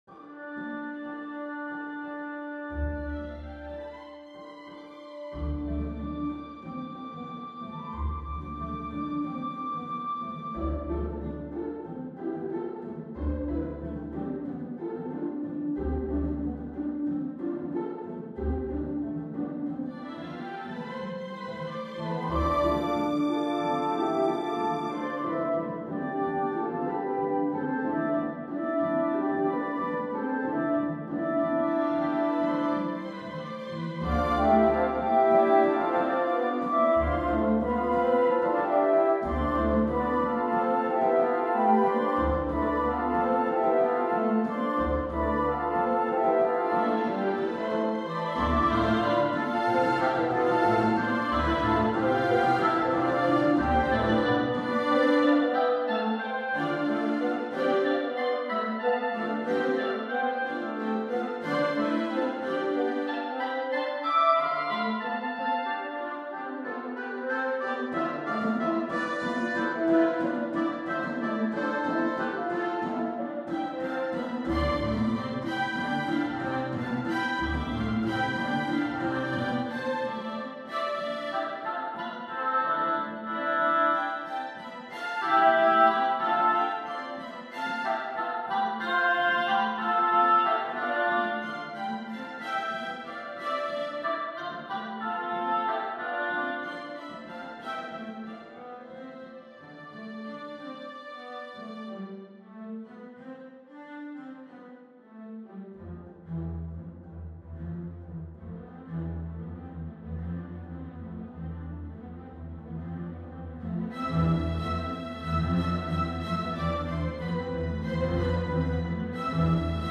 for orchestra
Orchestra: 2222; 2200; 1perc; strgs
The overall aura of the work has an touch of sadness.